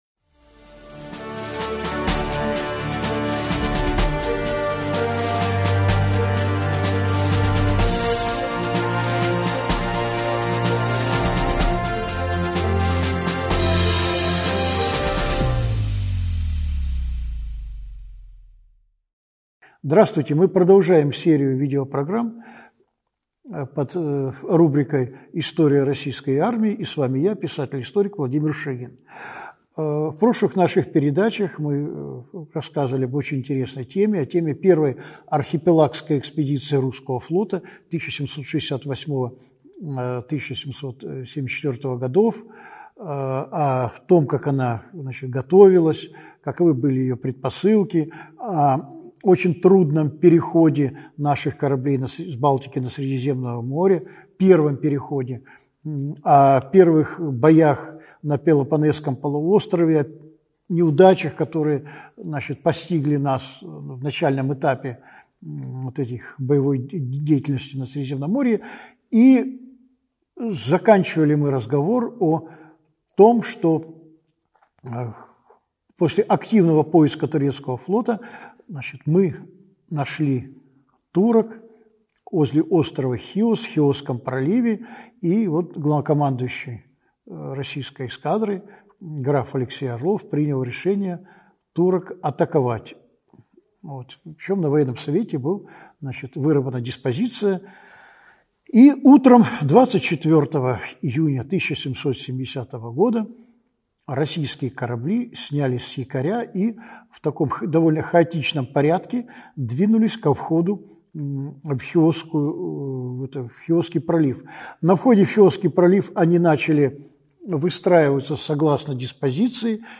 Аудиокнига Чесменская победа. Часть 3 | Библиотека аудиокниг